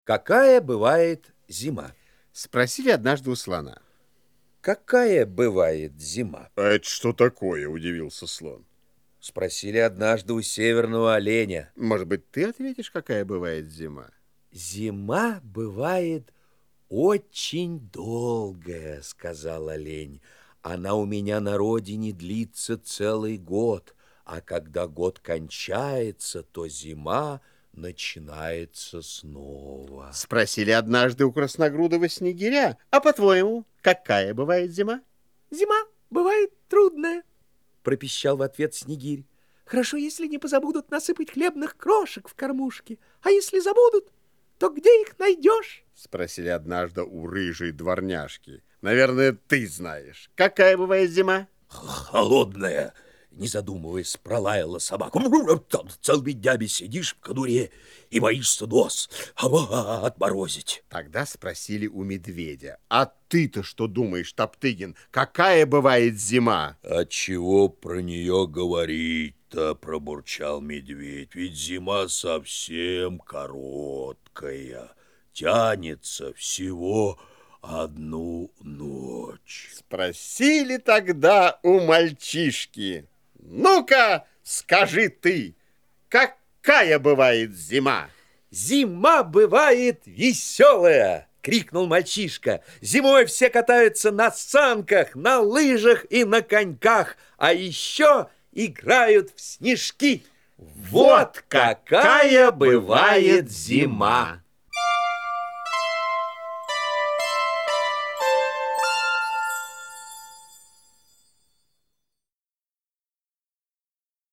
Аудиосказка «Какая бывает зима»
Текст читают Михаил Державин и Александр Ширвиндт.